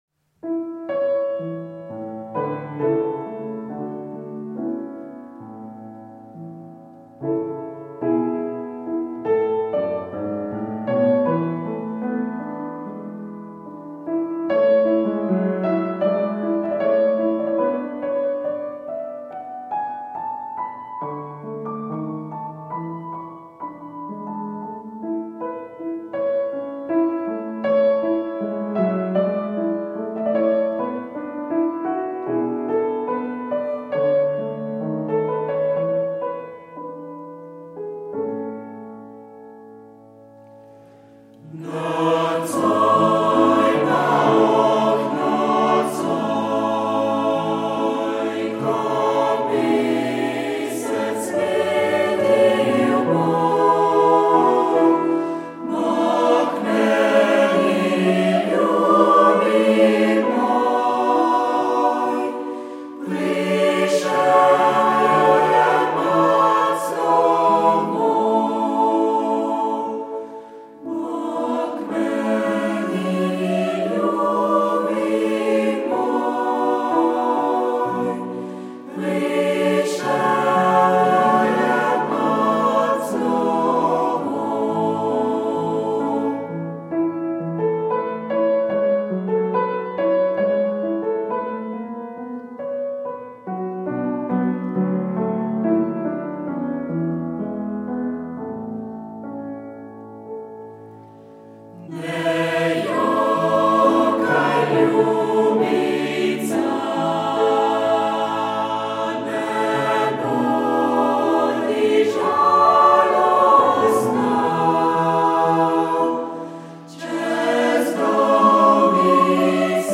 primorska narodna prir.